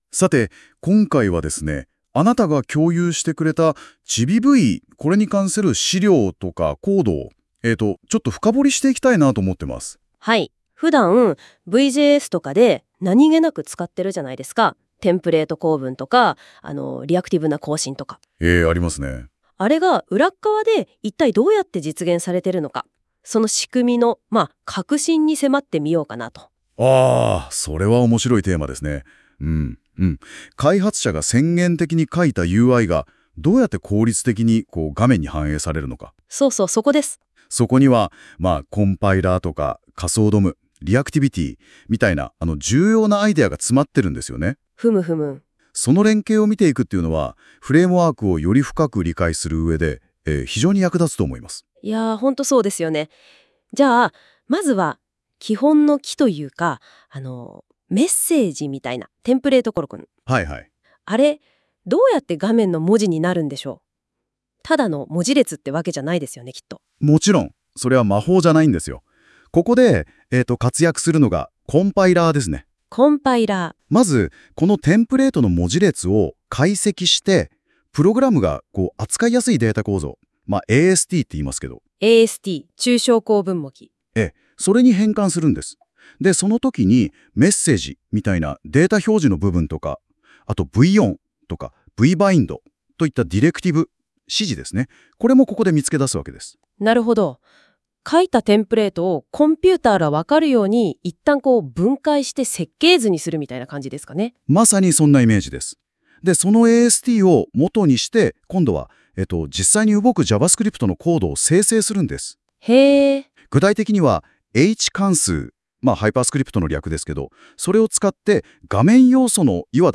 NotebookLM でポッドキャストにしてみた